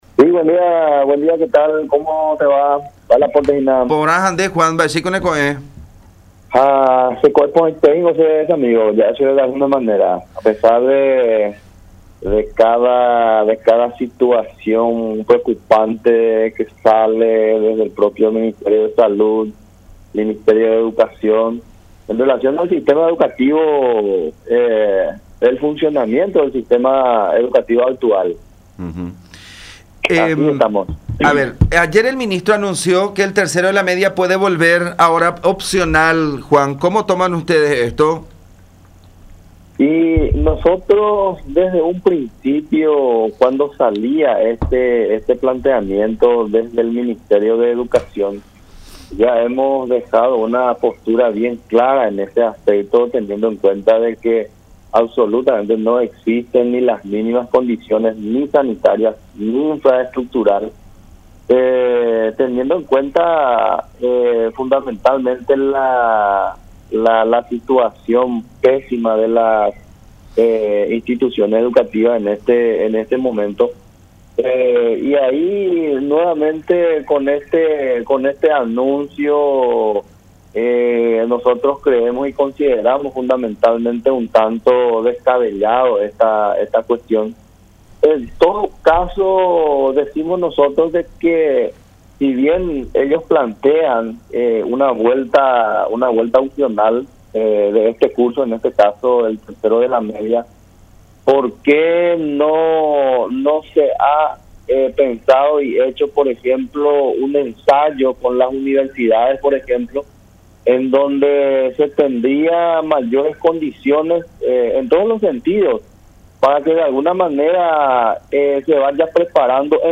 en conversación con La Unión.